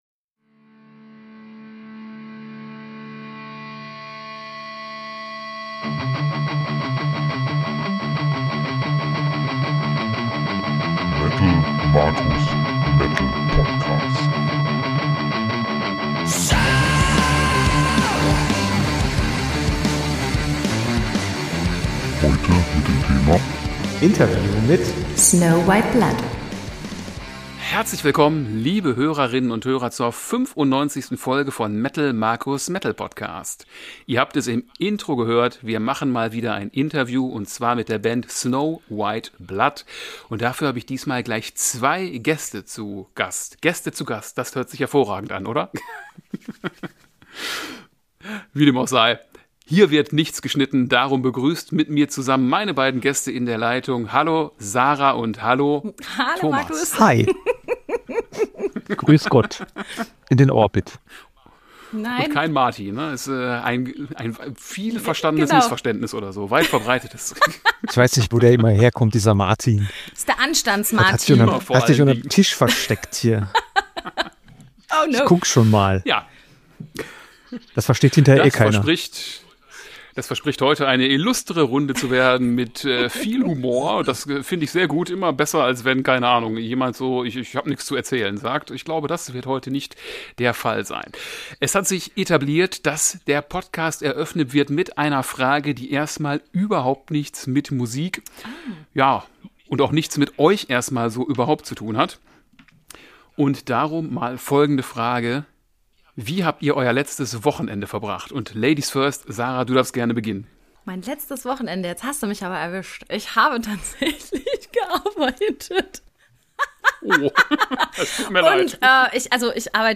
Folge 95 - Interview mit Snow White Blood